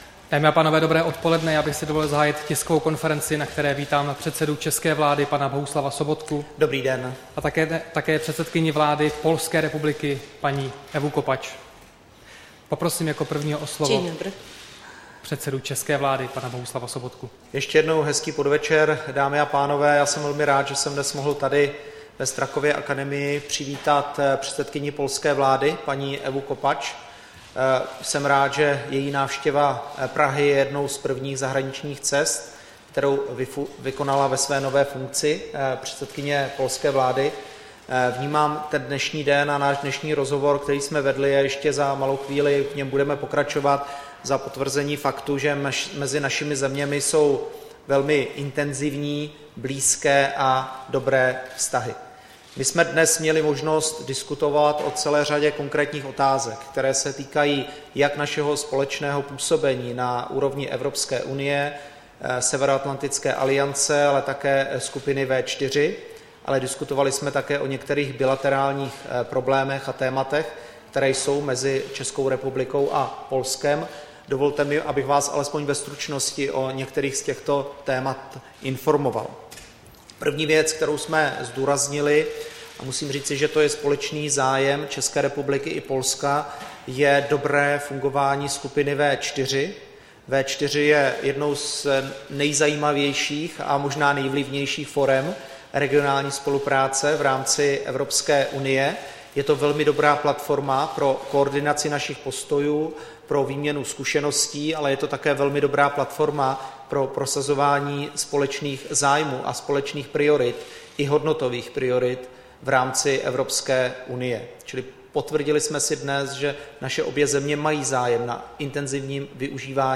Tisková konference po setkání premiéra s předsedkyní vlády Polska Ewou Kopacz